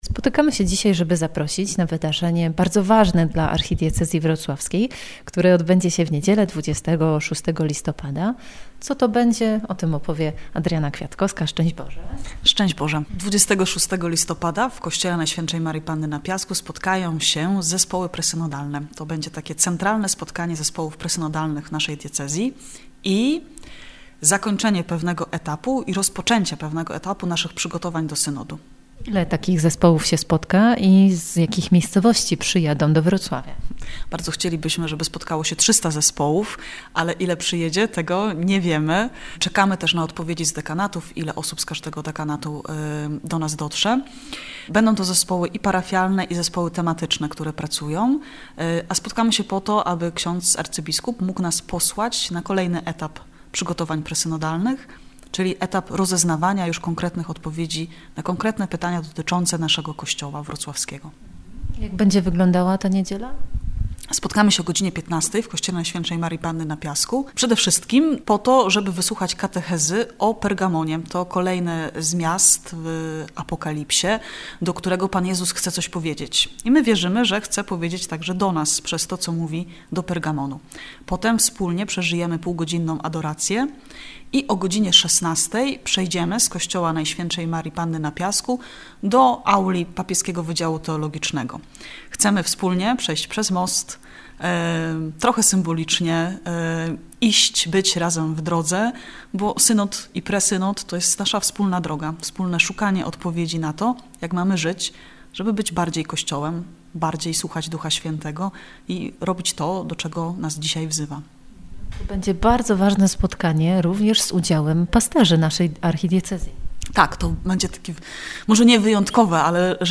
Studio Synodalne w Radiu Rodzina w czwartki po godz. 13:10.